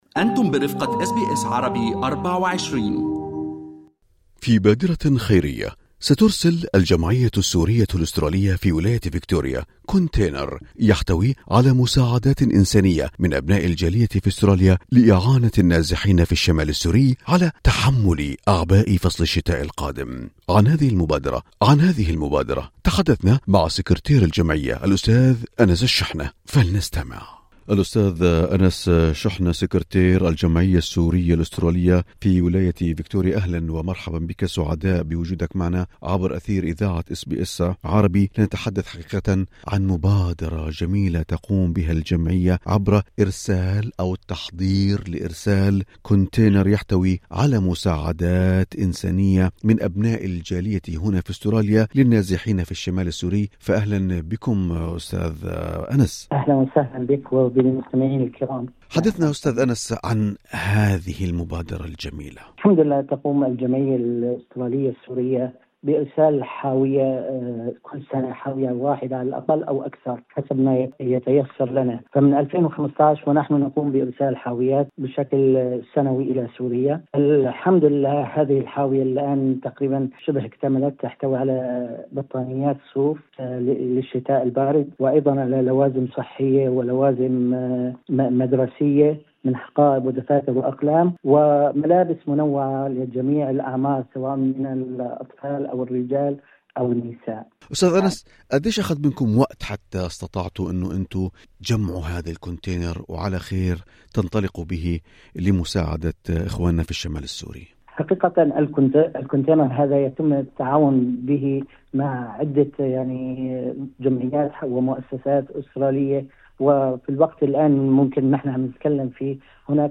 في حديثه مع إذاعة أس بي أس عربي